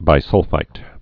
(bī-sŭlfīt)